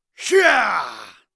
cheers1.wav